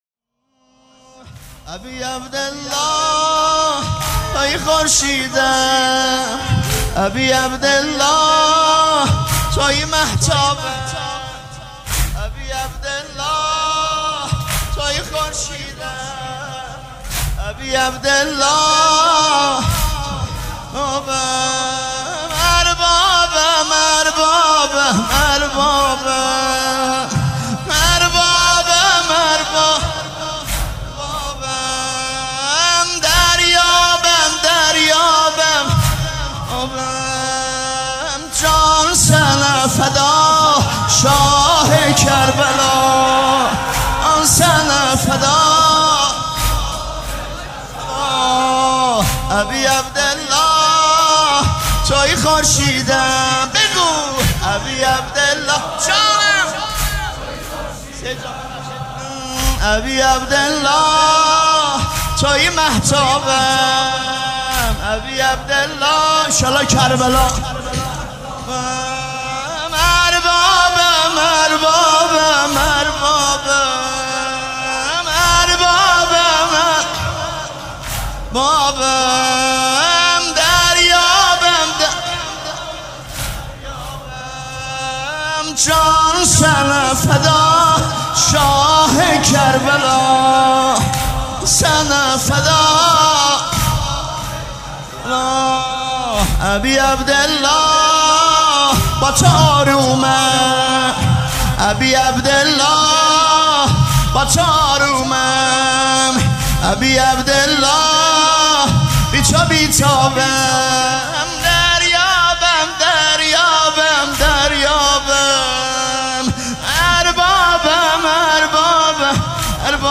مداح
فاطمیه
مراسم عزاداری شب اول